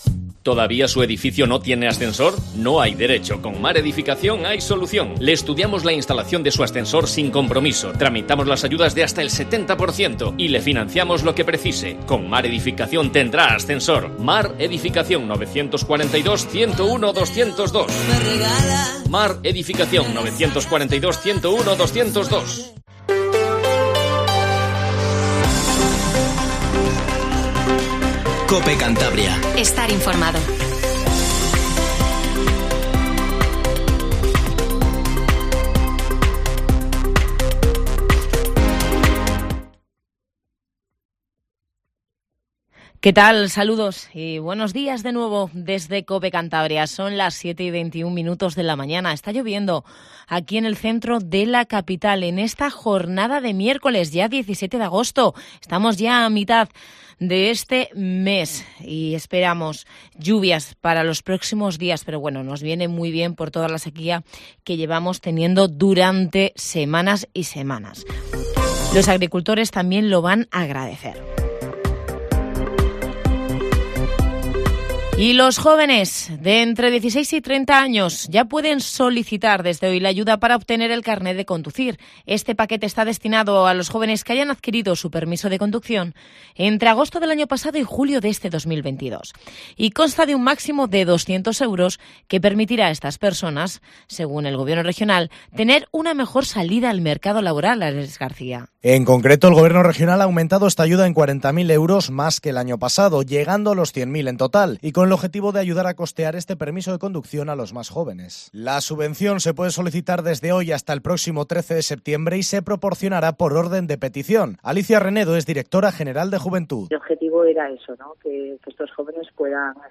Informativo Matinal Cope